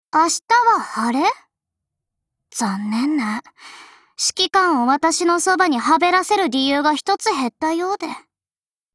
贡献 ） 协议：Copyright，人物： 碧蓝航线:菲利克斯·舒尔茨语音 您不可以覆盖此文件。